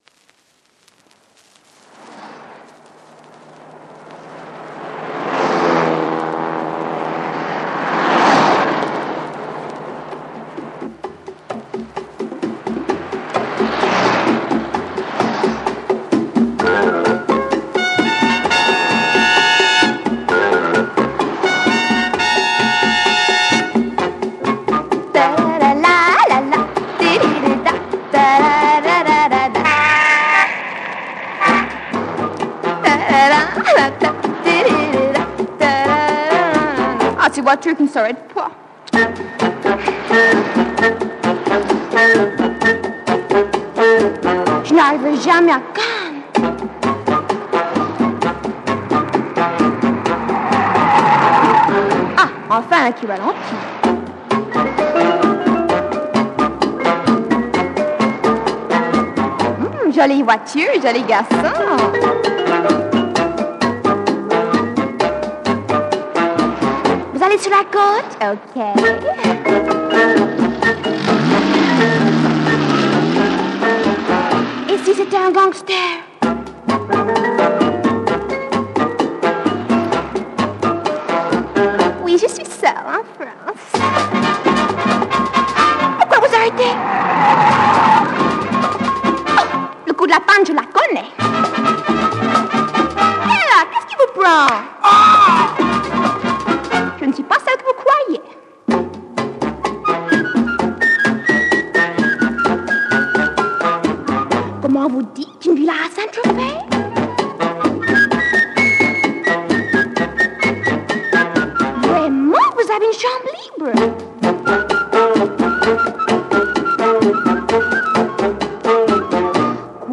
French female latin jazz